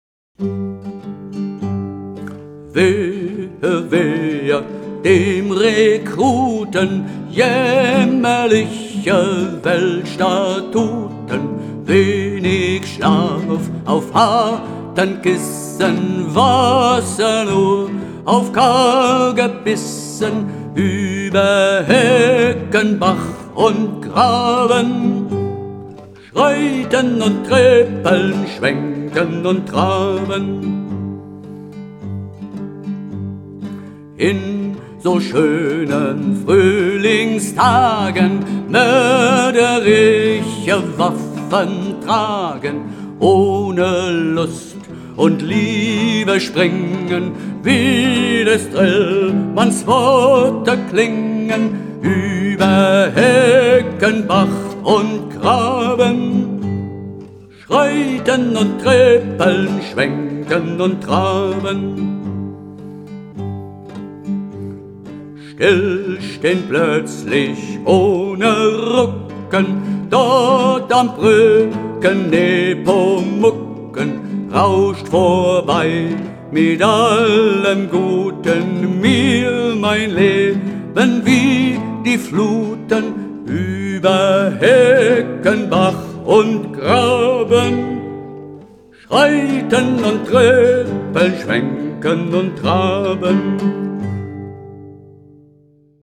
Die Hörprobe stammt von einer Studioaufnahme aus dem Jahr 2015.